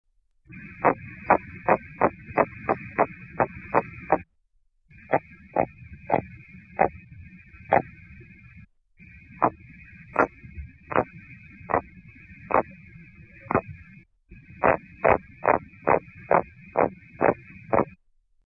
Type of sound produced grunts
Sound mechanism pharyngeal teeth stridulation amplified by adjacent swim bladder (similar to Haemulon album)
Behavioural context under duress (manual stimulation), easily induced sound production
Remark recordings of four specimens, low pass noise reduction filter (< 2800 Hz) applied to recording